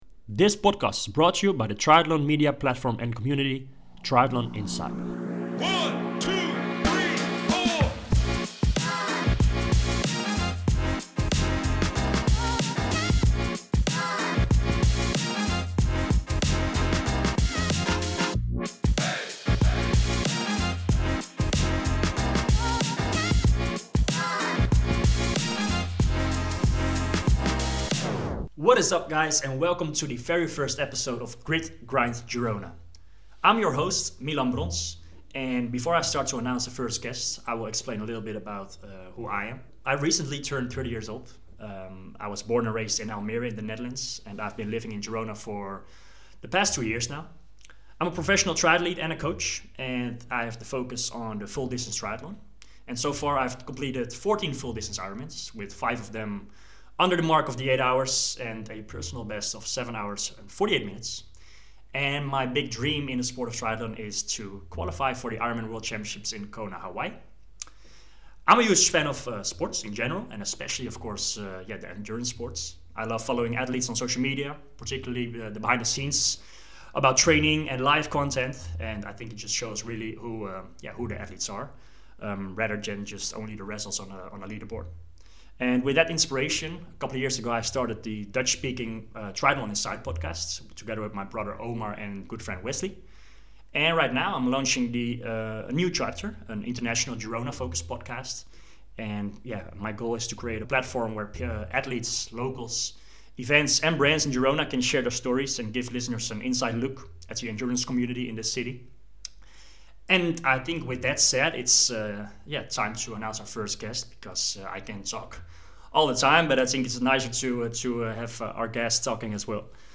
In this first episode we sit down with Vincent Luis to talk about his incredible triathlon career so far, why he calls Girona home, his favorite things to do here, signing a contract with the T100 triathlon series, and the possibility of a long-distance debut in 2025.